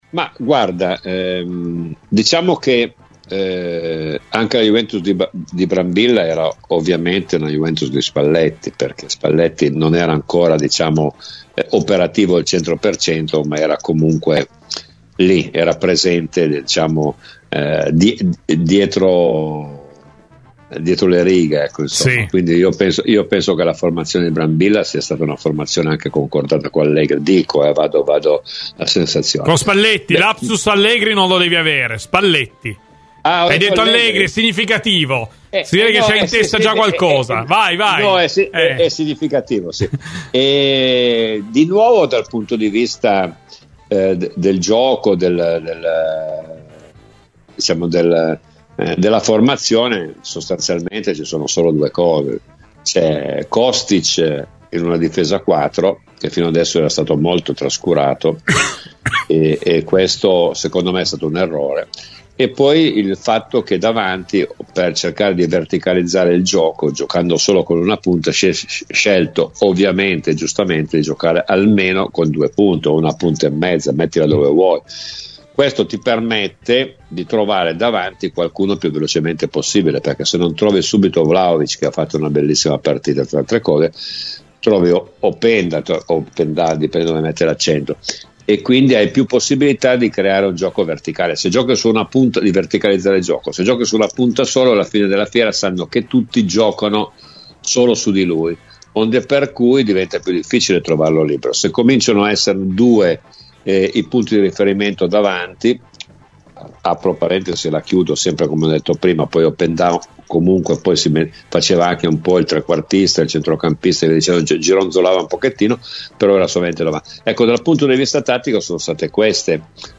Ospite di "Cose di Calcio" su Radio Bianconera, Domenico Marocchino ha commentato la vittoria della Juventus sulla Cremonese, focalizzandosi su di un paio di giocatori in particolare: "Ho visto un buon Kostic, giocatore che forse era stato messo troppo presto da parte da Tudor, e mi è piaciuta la decisione di Spalletti di giocare con Vlahovic e Openda insieme, perchè la presenza di due punte ha mandato un po' in confusione la Cremonese, a cui è mancato il punto di riferimento su cui concentrare i propri sforzi difensivi.